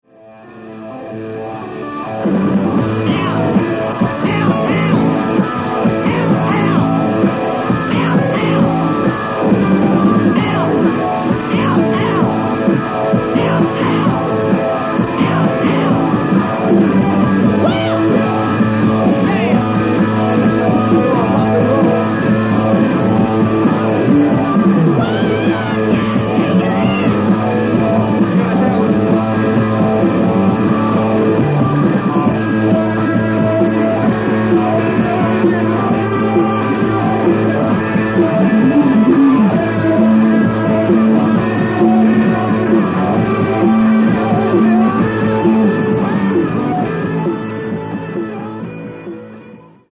Venue: Santa Monica Civic Auditorium / Santa Monica, CA
Source:  TV Broadcast Recording
Lead Guitar, Backing Vocals
Keyboards, Guitar, Backing Vocals
Drums, Backing Vocals